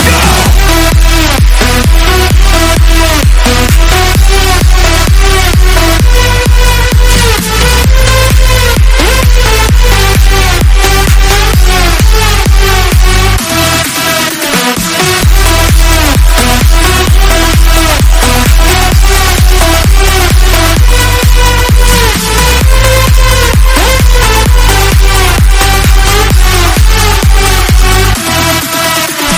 High quality mp3 ringtones.